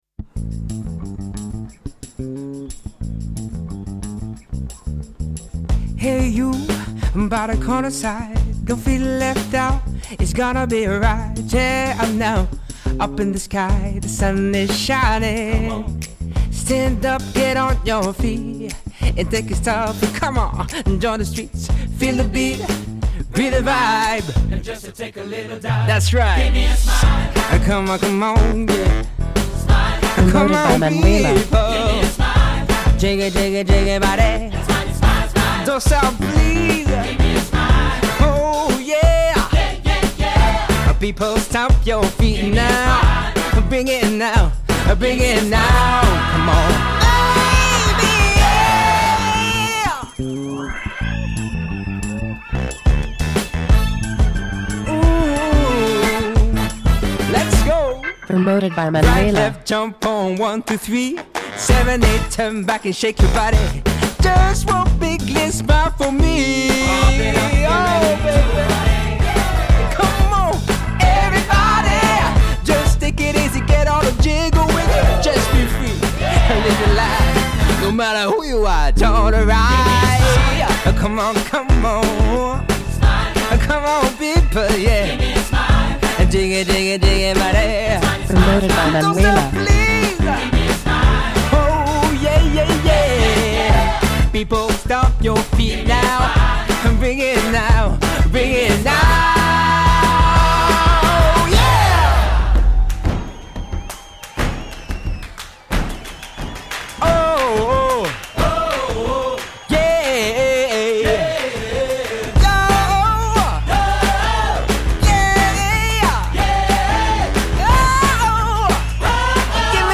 Radio Edit